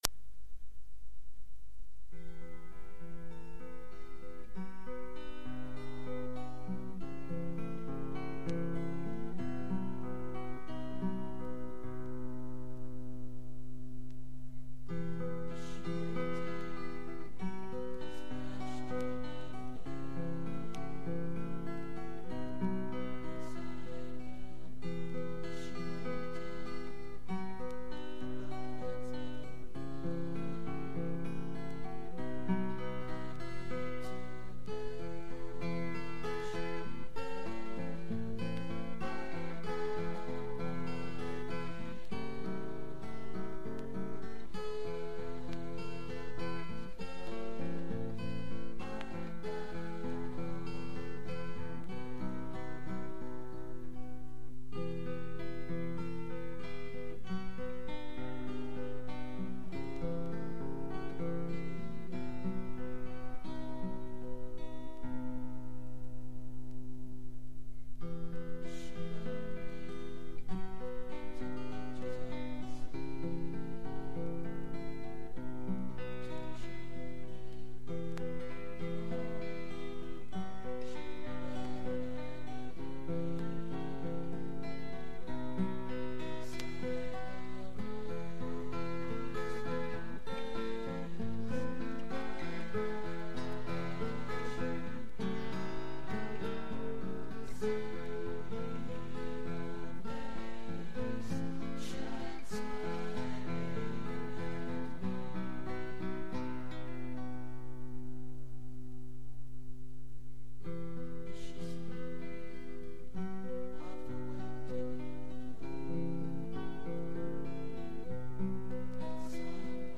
rimasto fedele al master originale